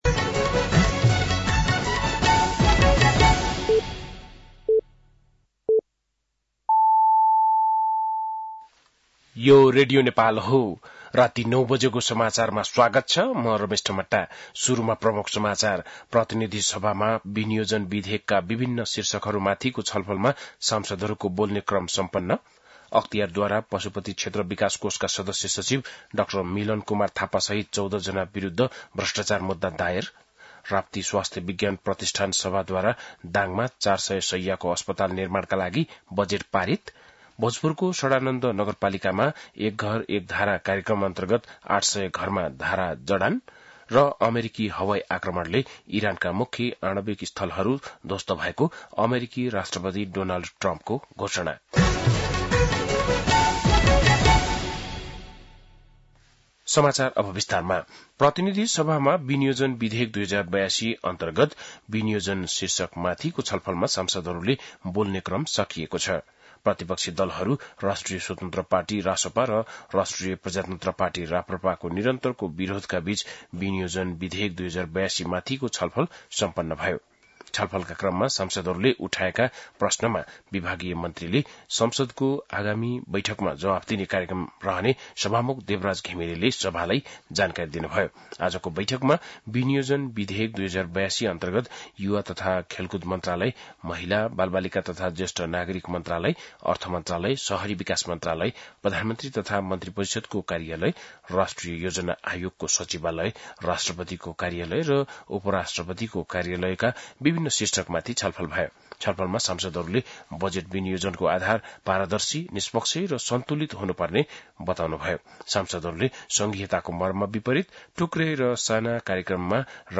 बेलुकी ९ बजेको नेपाली समाचार : ८ असार , २०८२
9.-pm-nepali-news-1-2.mp3